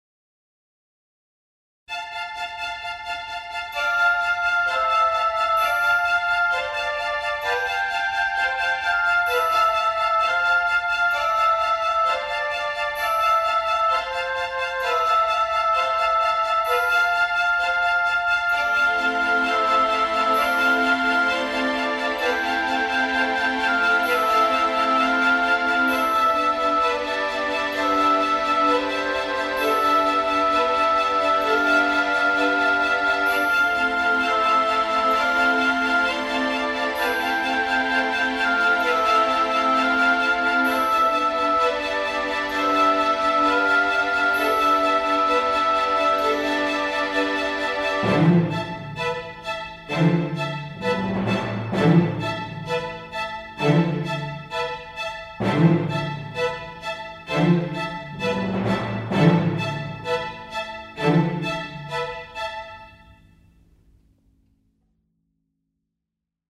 BGM
ショート明るい穏やか